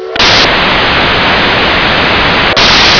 AMERICAN WHISTLES
np5chime.wav